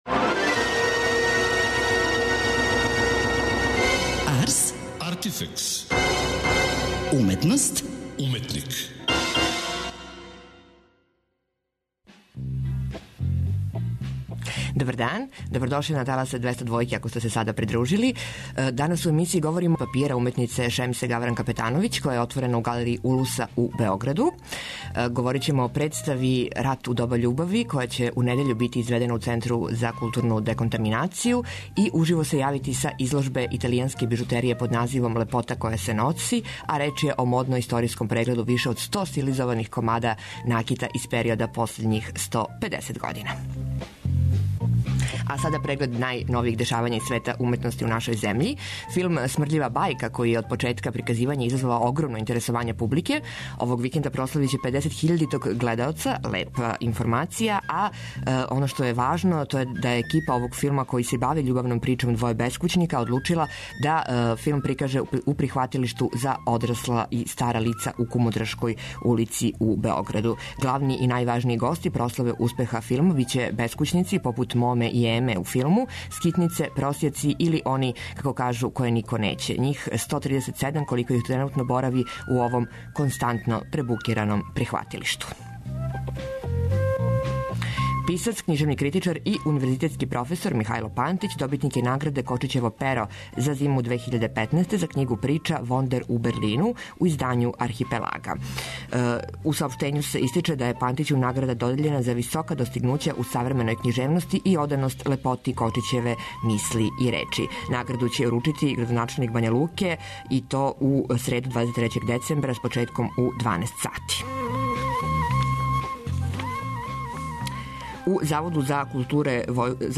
Уживо се јављамо са отварања изложбе италијанске бижутерије "Лепота која се носи" . Реч је о модно-историјском прегледу више од 100 стилизованих комада накита из периода последњих 150 година.